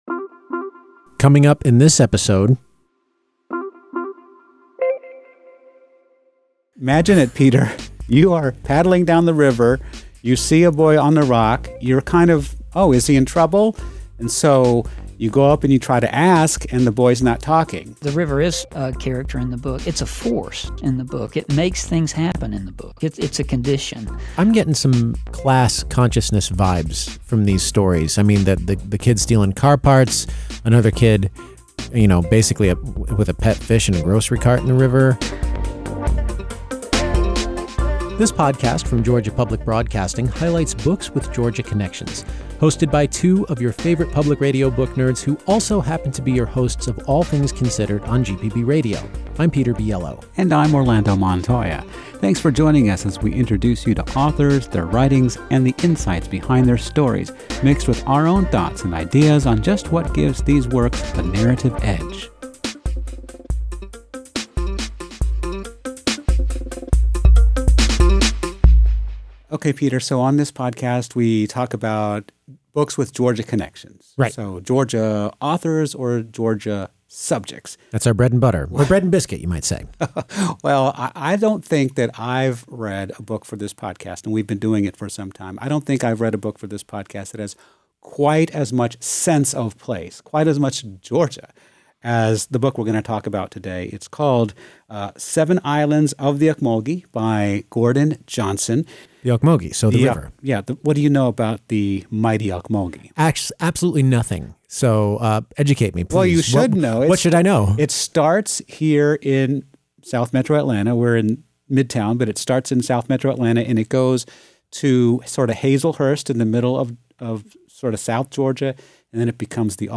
… continue reading 60 episodes # Society # Books # Arts # Georgia Public Broadcasting # Lifestyle # Hobbies # Read # Review # NPR # GPB # Interviews # Authors